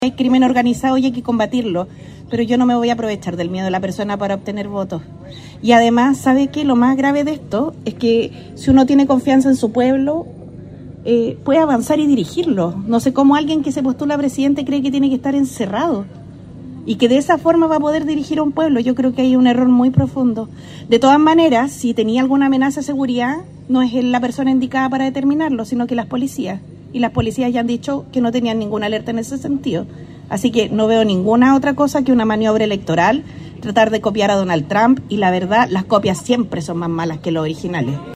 Previo al acto oficial, Jara conversó en la Plaza Independencia con la prensa local, en una instancia en la que estuvo acompañada por alcaldes y parlamentarios del oficialismo en el Biobío.